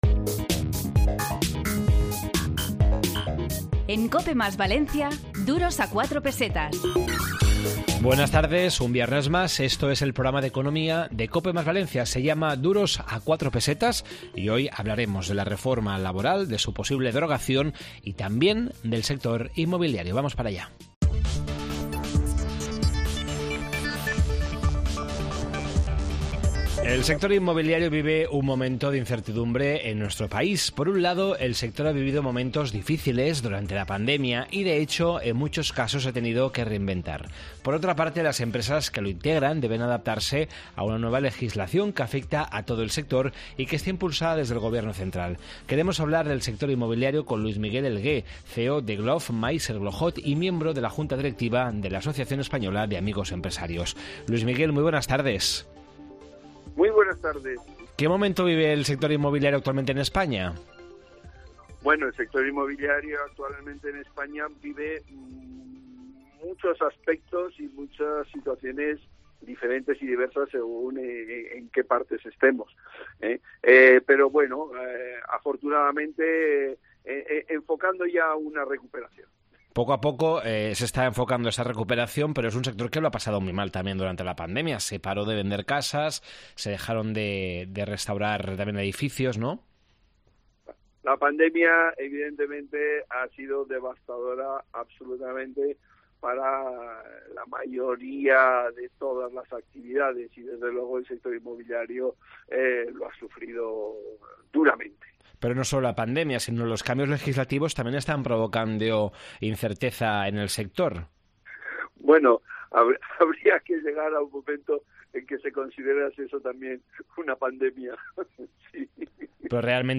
Esta semana en Duros a 4 Pesetas de COPE + Valencia, en el 92.0 de la FM, hemos preparado un programa dedicado al mundo inmobiliario, la reforma laboral y la sentencia del Tribunal Constitucional sobre plusvalías.